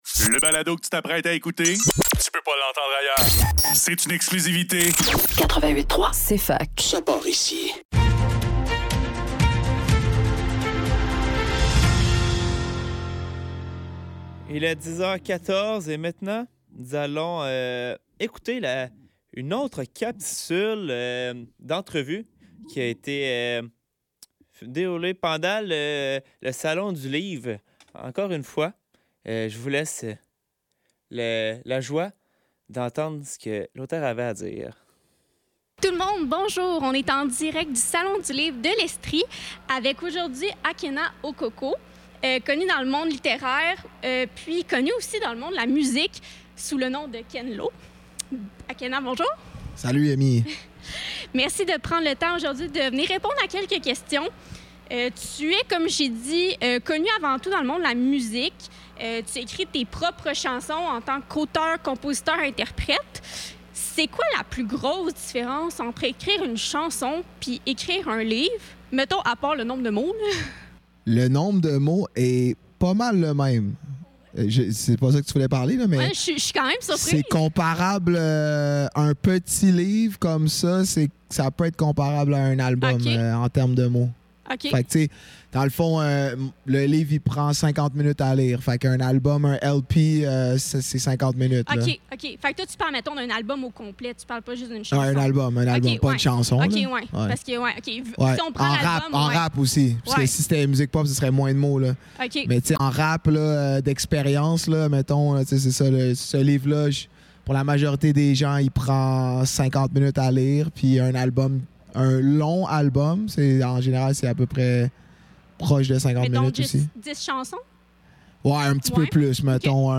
Le neuf - Salon du livre de l'Estrie